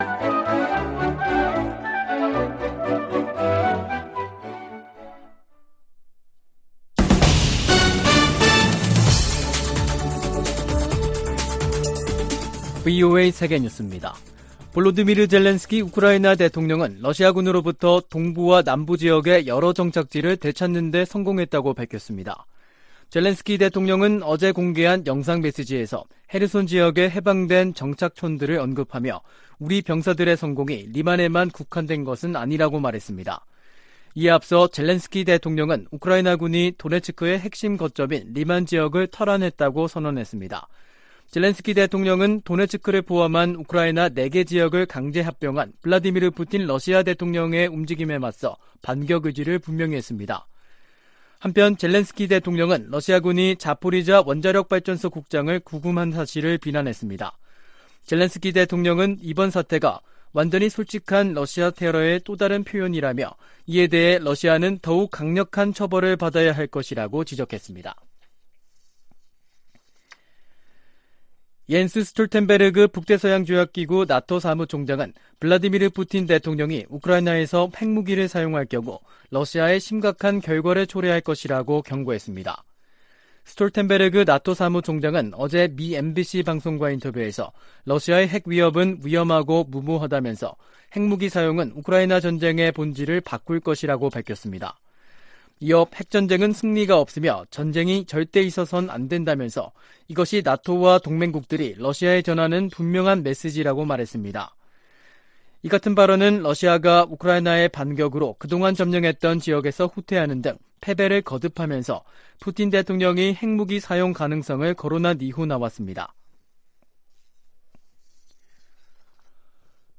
VOA 한국어 간판 뉴스 프로그램 '뉴스 투데이', 2022년 10월 3일 3부 방송입니다. 북한이 지난 1일 동해상으로 탄도미사일(SRBM) 2발을 발사해 지난 달 25일 이후 총 7발의 미사일을 발사했습니다. 미 국무부는 잇따른 탄도미사일 발사로 안정을 흔드는 북한의 무기 역량을 제한하겠다는 의지를 나타냈습니다.